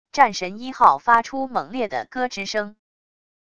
战神一号发出猛烈的咯吱声wav音频